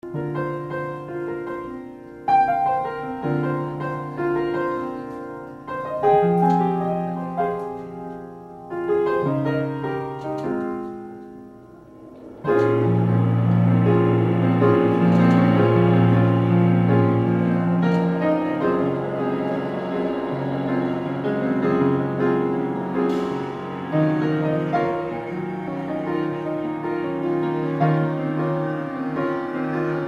Recording: Feb.〜Sep.1999 　 at　 Holly's’ & Swing　Sing
息の合ったインプロビゼーションをお楽しみください。